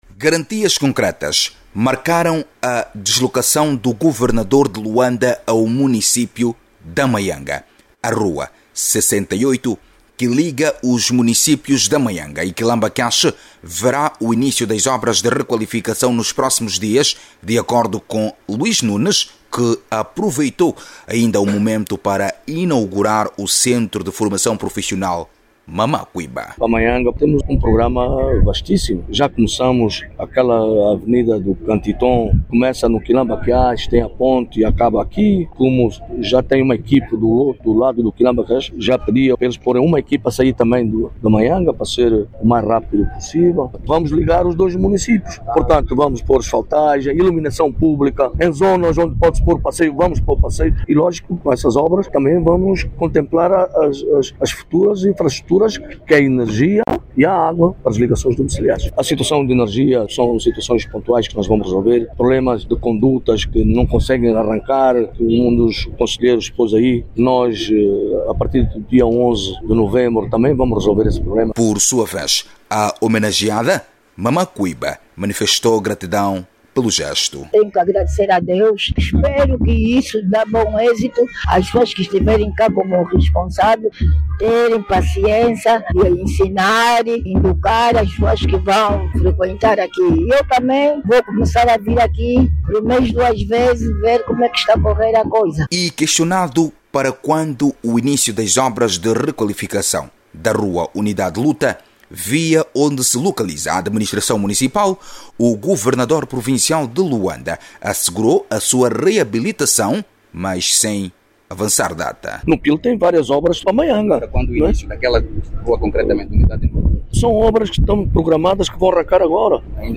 Durante a visita, o governador Luís Nunes assegurou ainda que está prevista a requalificação da Rua Unidade e Luta, também conhecida como “Rua da Administração”, cujos trabalhos poderão iniciar em breve. Jornalista